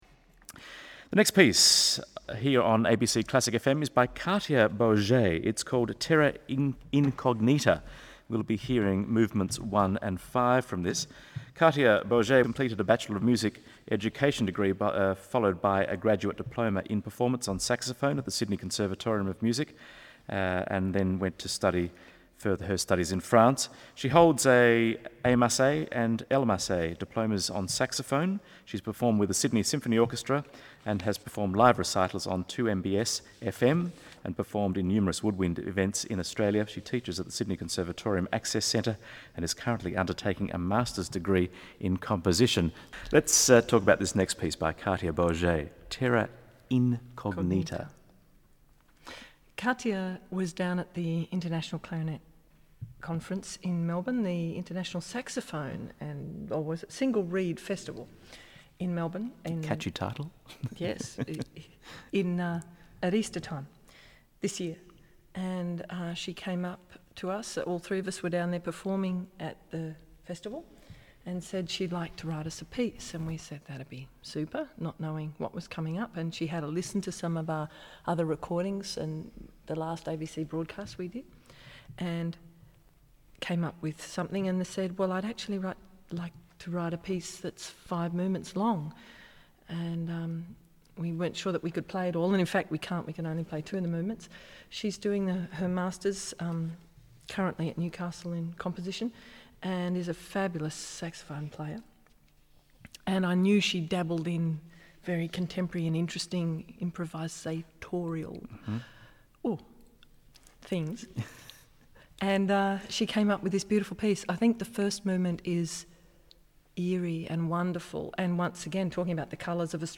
2005 ABC live talk about Terra Incognita